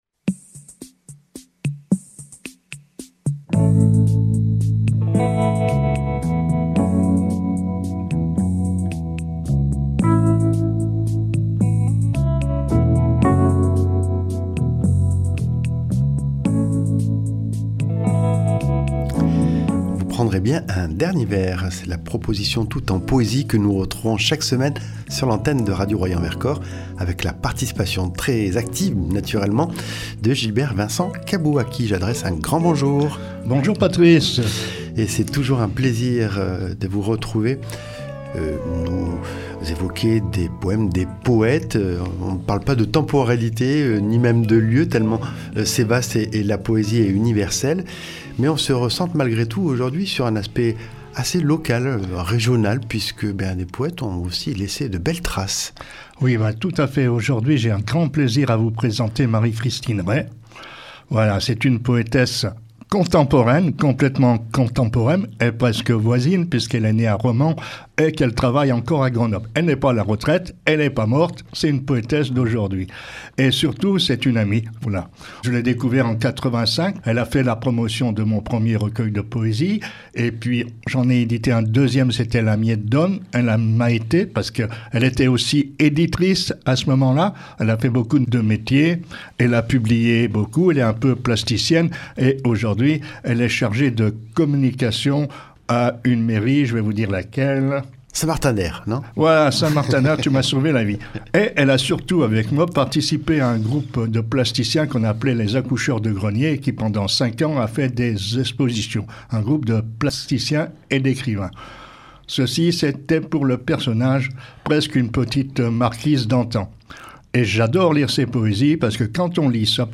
Au programme : lecture d’un poème par semaine accompagnée d’une petite biographie de l’auteur ou l’autrice. Le choix des poètes et poétesses se fait de manière totalement subjective, loin des locataires de Lagarde et Michard et souvent en prise avec l’actualité.